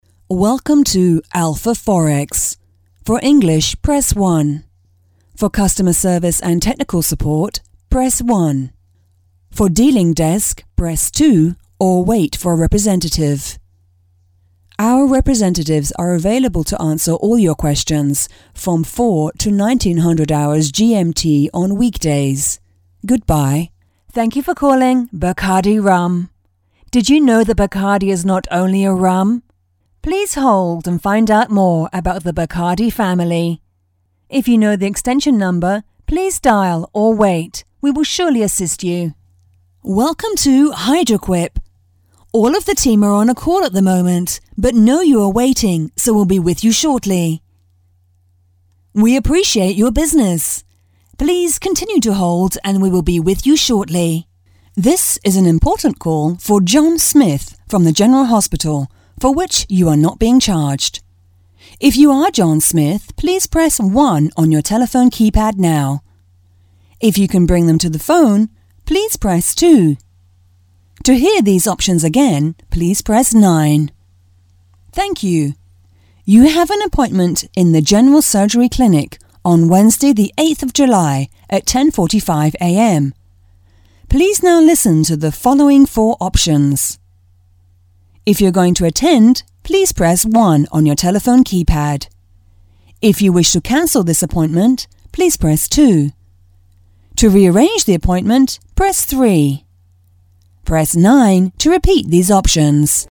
British Female Voice Over IVR Demo
Voz Madura 01:43
- Broadcast quality recording from her professional home studio